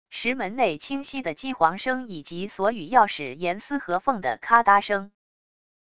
石门内清晰的机簧声以及锁与钥匙严丝合缝的咔哒声wav音频